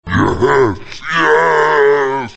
demonic_snore